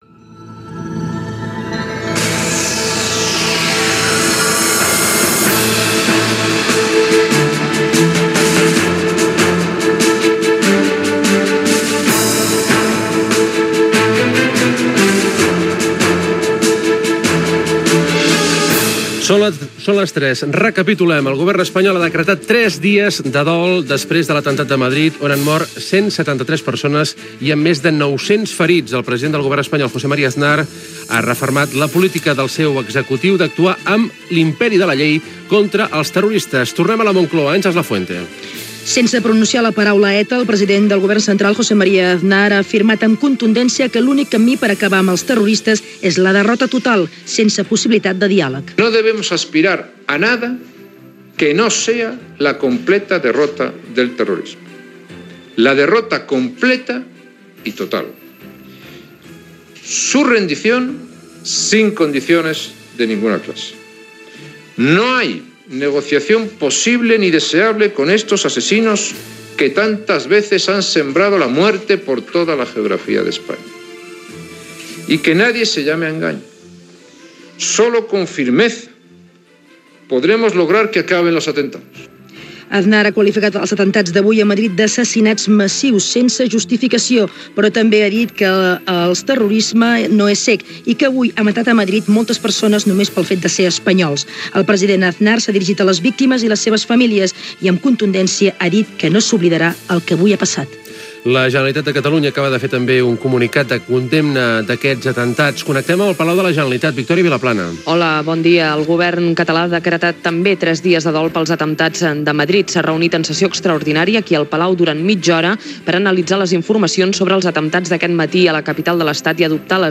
Hora, recapitulació dels fets i declaracions relacionades amb els atemptats terroristes a quatre trens de rodalies a Madrid. Indicatiu de la ràdio
Informatiu